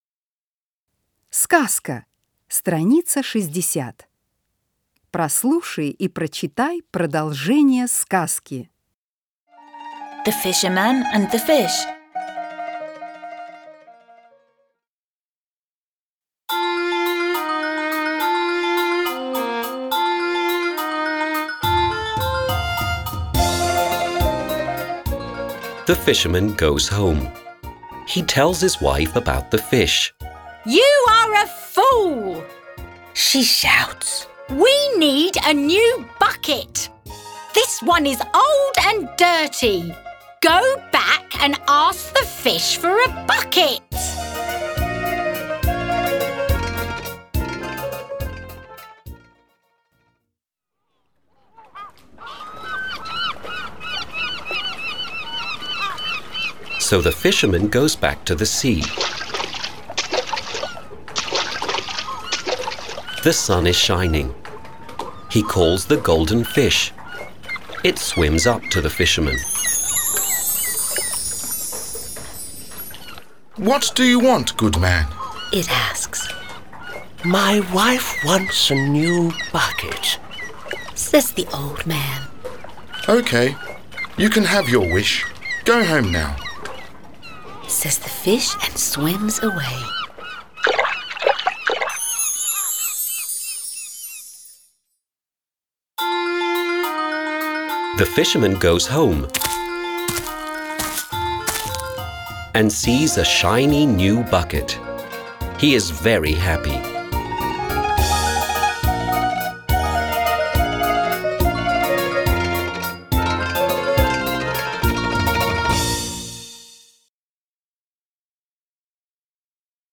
09-Сказка-с.-60-.mp3